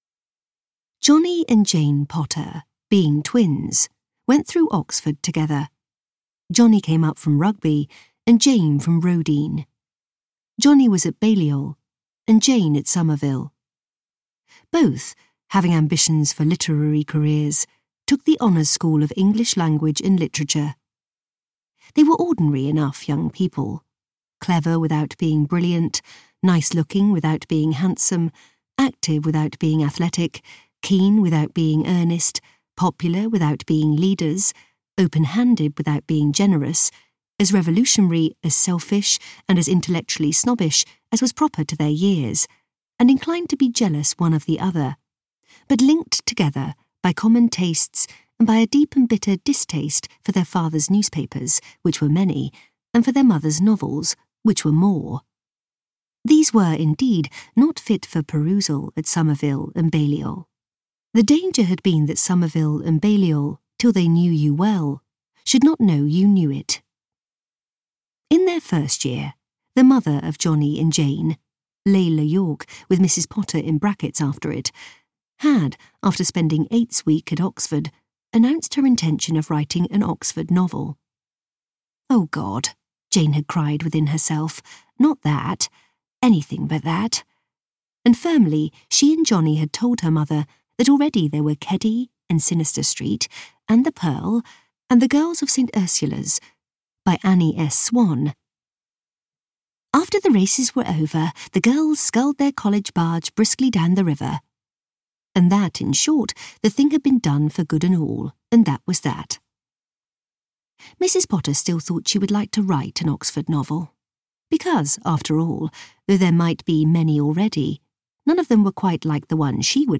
Potterism audiokniha
Ukázka z knihy